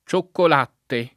cioccolata [©okkol#ta] s. f. — anche cioccolato [©okkol#to] s. m. — molto più com. la forma femm. per indicare la bevanda; un po’ più com. la forma masch. per il prodotto solido o in polvere — antiq. cioccolatte [